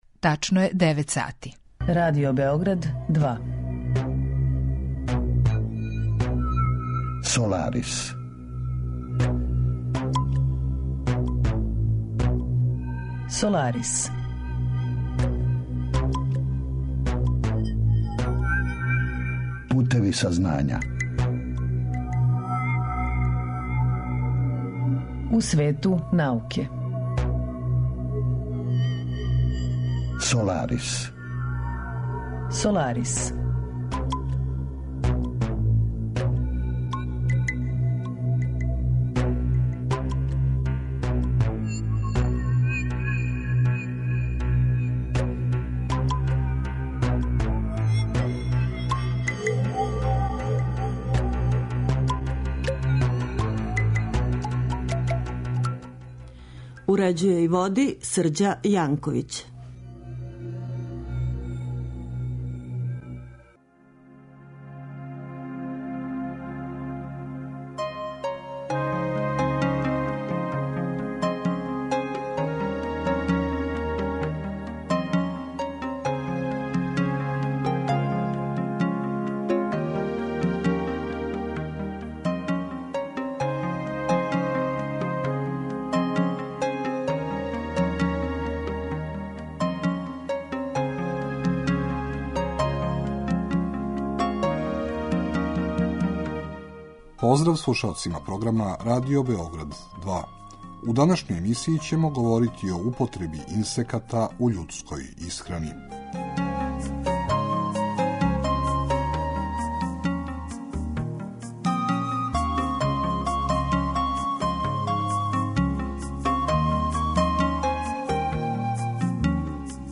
Тема емисије: Инсекти у људској исхрани, a саговорница